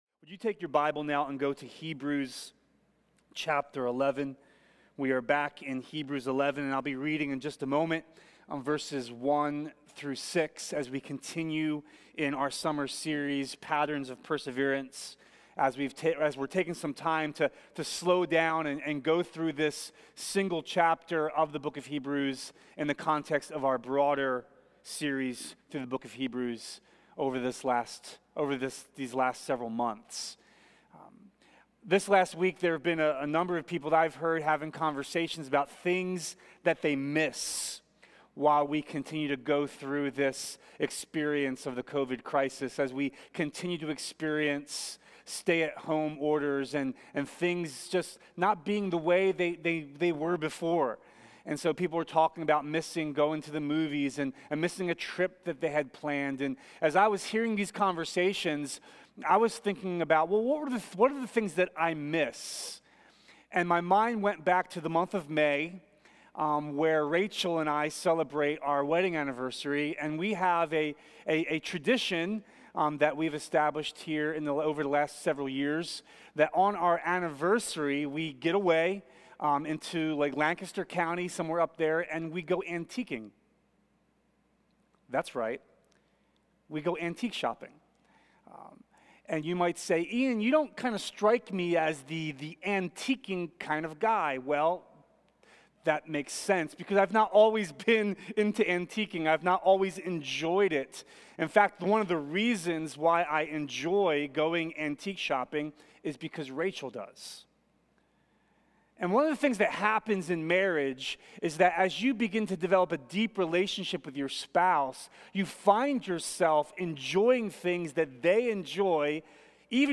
Covenant Community Church | West Philadelphia Reformed Charismatic Church